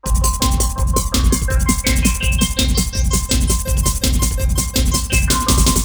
__BEEP POP 4.wav